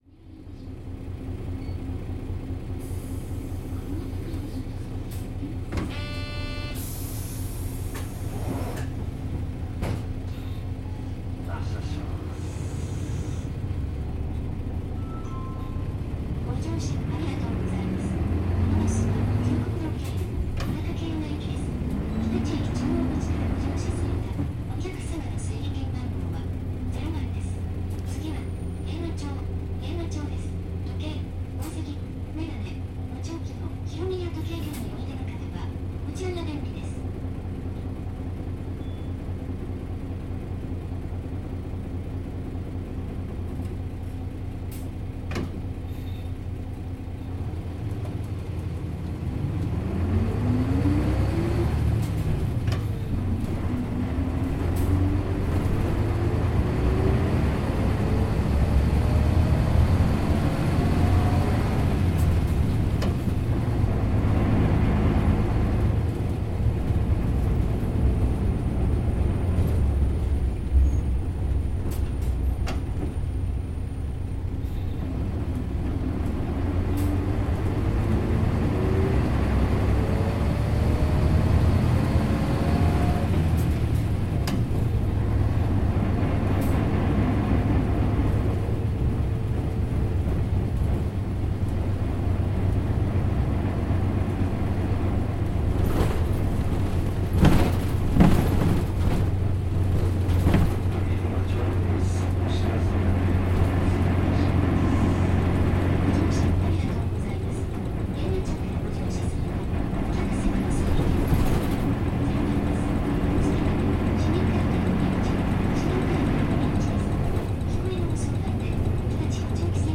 zoom H2n